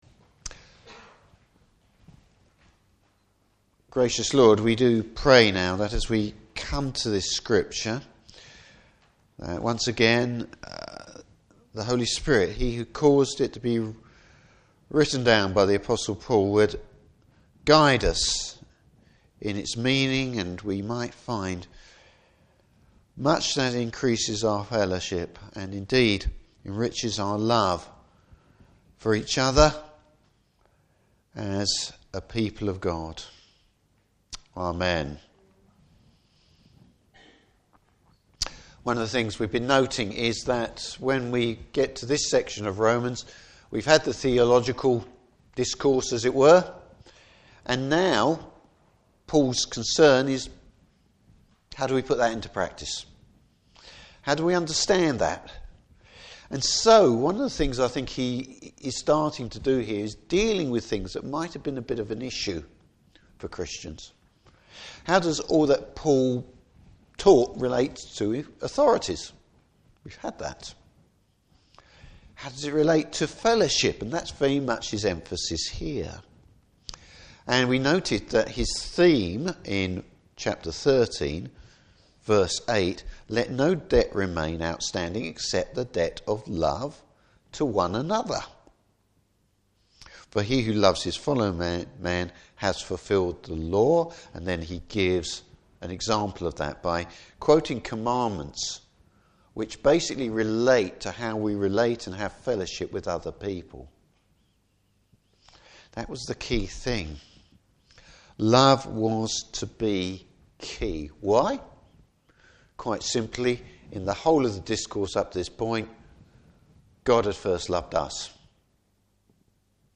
Service Type: Morning Service Keeping unity and fellowship.